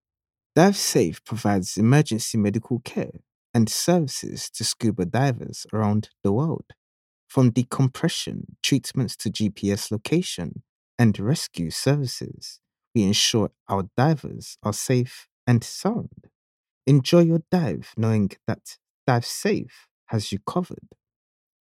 Male Voice Over Talent, Artists & Actors
English (Caribbean)
Yng Adult (18-29) | Adult (30-50)